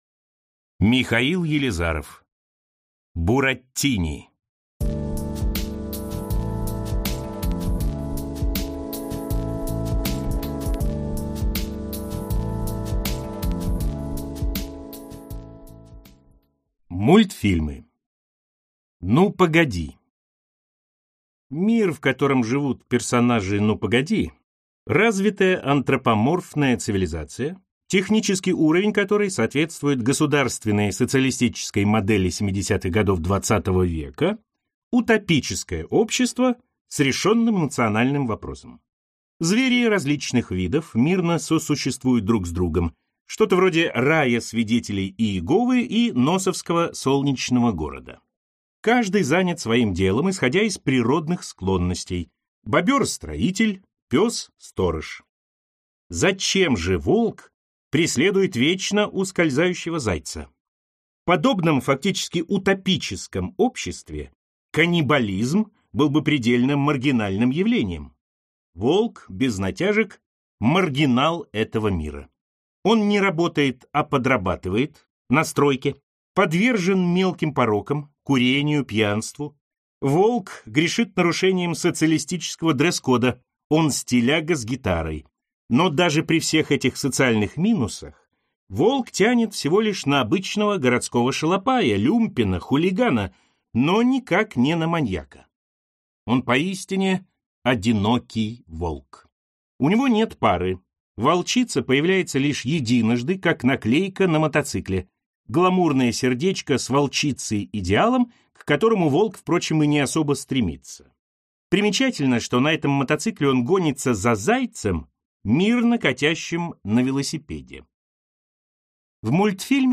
Аудиокнига Бураттини | Библиотека аудиокниг